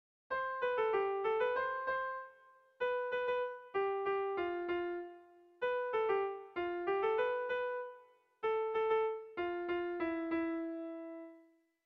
Melodías de bertsos - Ver ficha   Más información sobre esta sección
Dantzakoa
AB